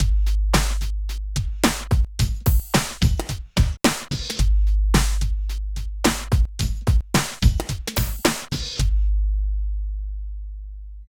103 LOOP  -R.wav